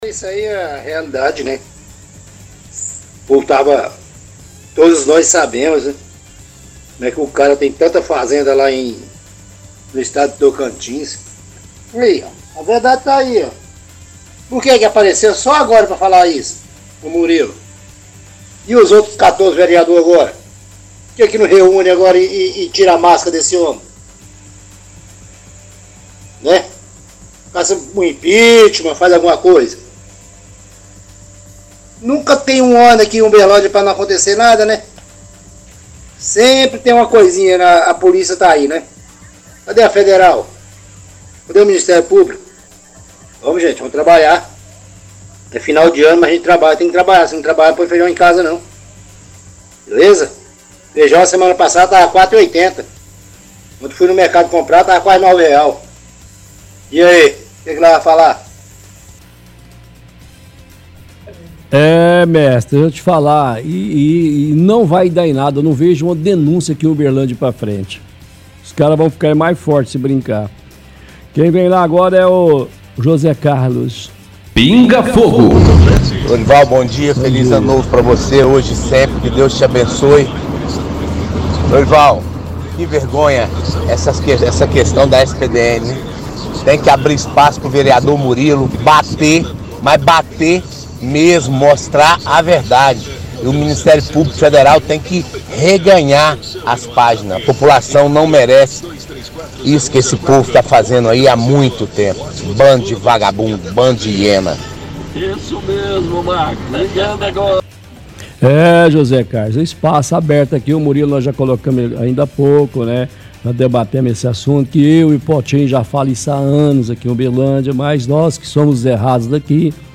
– Ouvinte reclama que a câmara não vai fazer nada com o caso da SPDM.
– Ouvinte reclama da questão da SPDM e pede que abra espaço para o vereador Murilo mostrar a verdade, pedindo que o MP investigue.